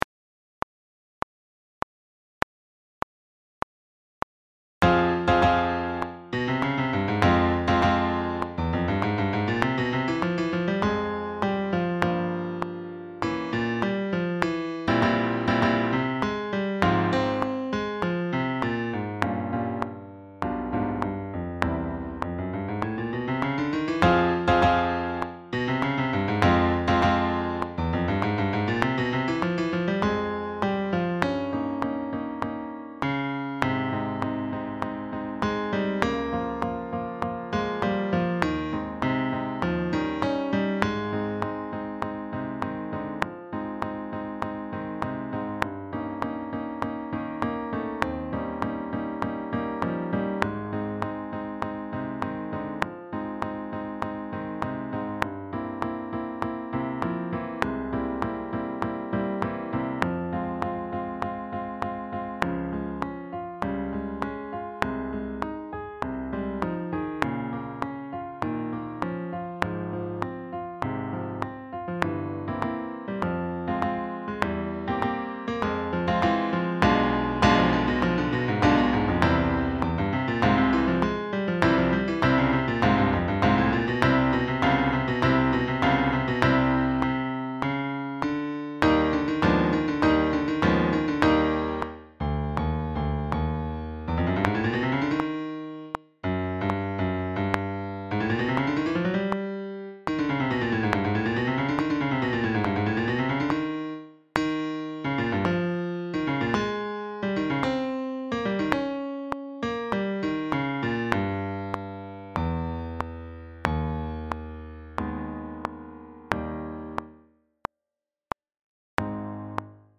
Backing track
164-4-septieme-solo-de-concert-backing-track.mp3